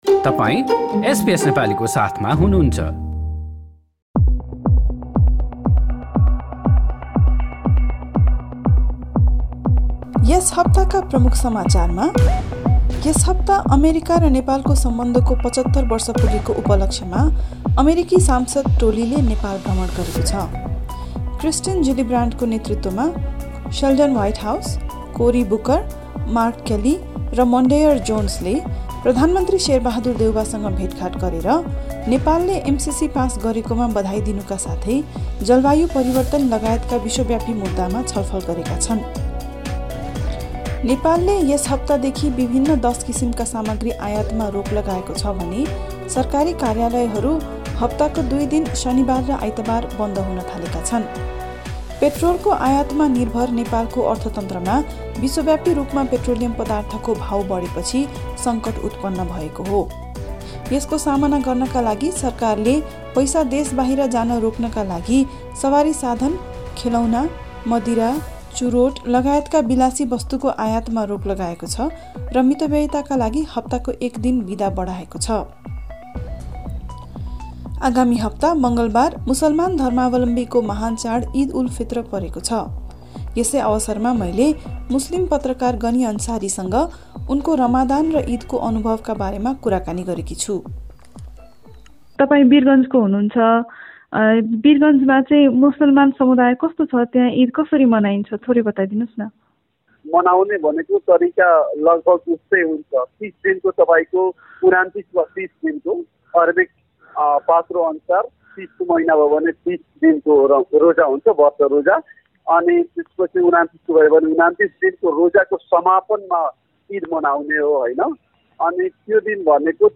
इद उल फित्रबारे विशेष कुराकानी